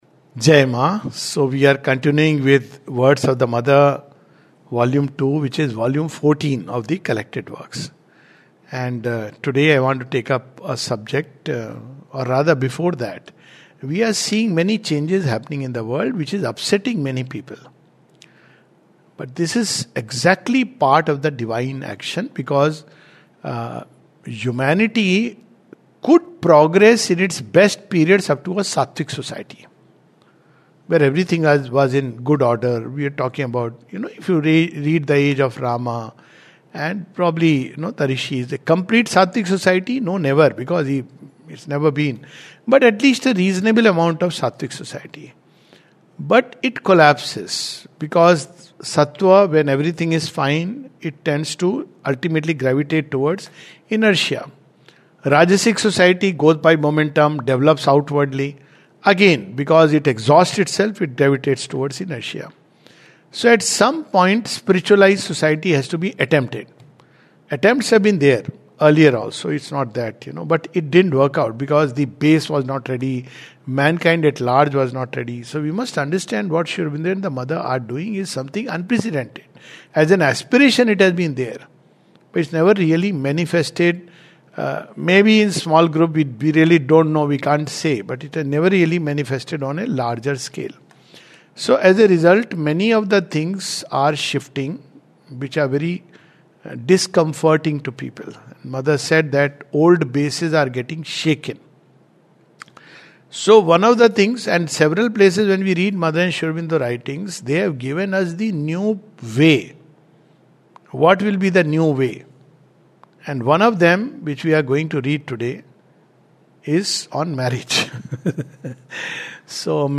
This is the last talk on Collected Works of the Mother, Volume 14.